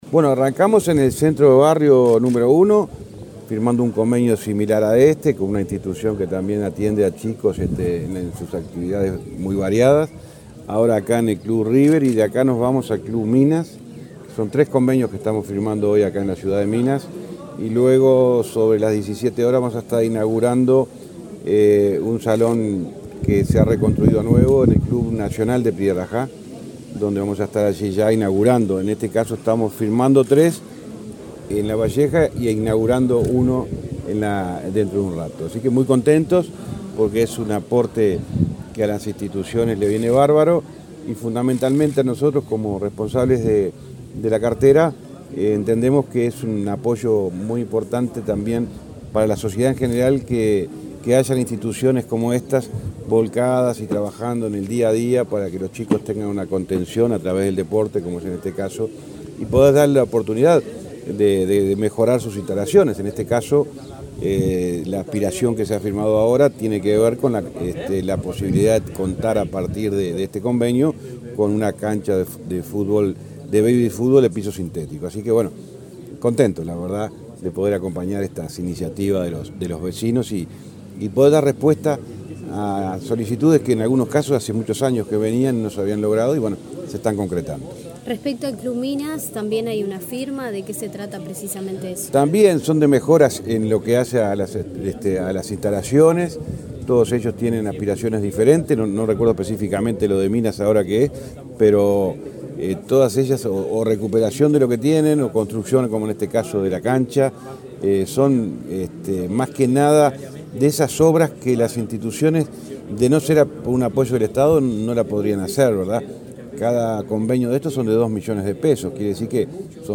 Declaraciones del ministro de Transporte, José Luis Falero
Este miércoles 1.° en Lavalleja, el ministro de Transporte, José Luis Falero, dialogó con la prensa, durante su recorrida por el departamento, donde